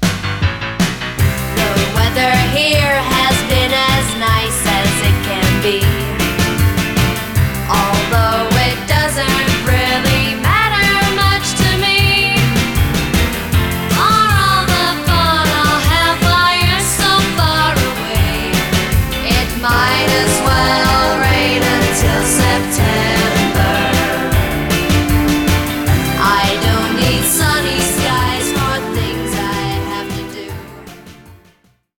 cover version